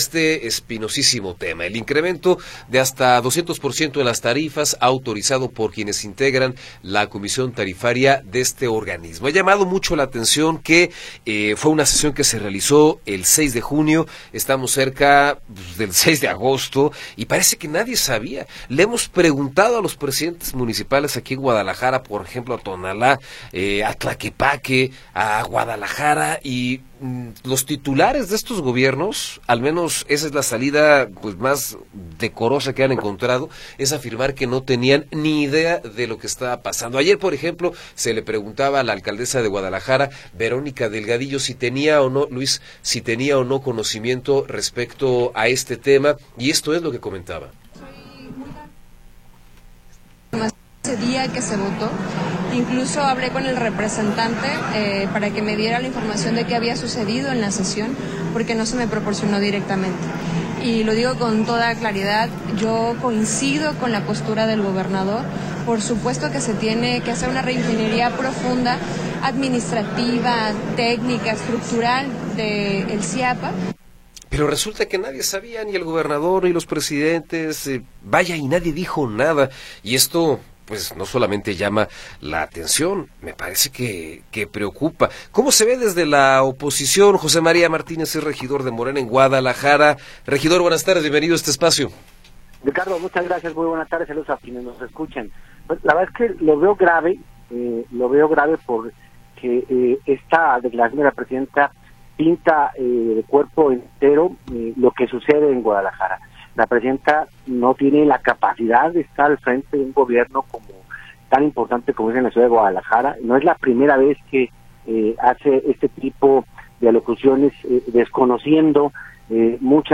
Entrevista con José María Martínez